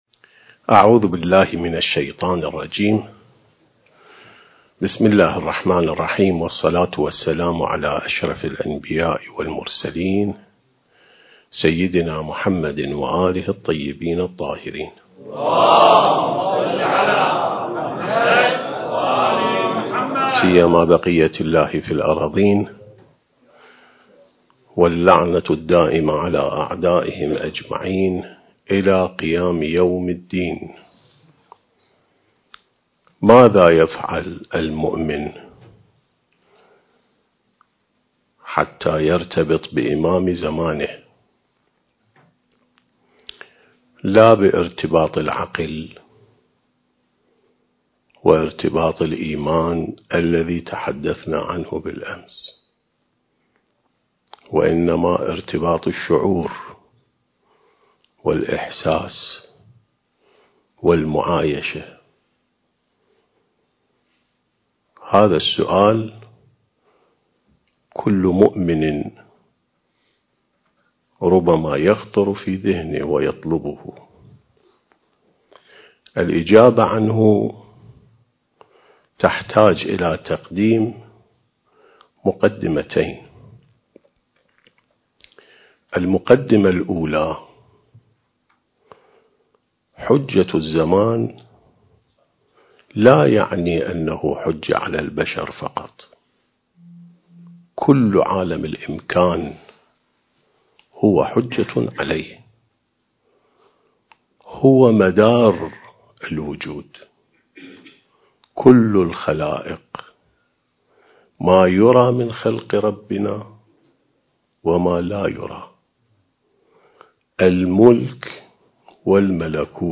عين السماء ونهج الأنبياء سلسلة محاضرات: الارتباط بالإمام المهدي (عجّل الله فرجه)/ (2)